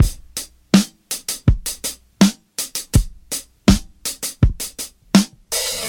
• 82 Bpm Modern Drum Beat E Key.wav
Free drum groove - kick tuned to the E note. Loudest frequency: 1388Hz
82-bpm-modern-drum-beat-e-key-BrC.wav